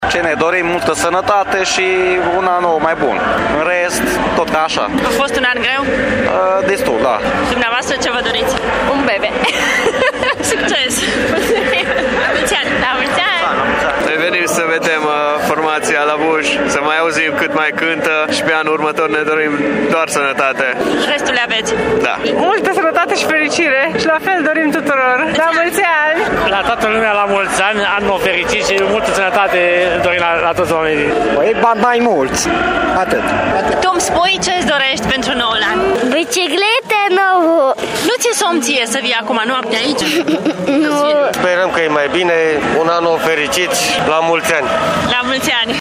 Oamenii au început să se adune în Piața Victoriei încă de la ora 10 seara pentru concert, la miezul nopții fiind adunați peste 10.000 de oameni, conform datelor Poliției Locale.
Cu speranța de mai bine, oamenii își doresc, în primul rând, sănătate în 2016 dar și mai mulți bani: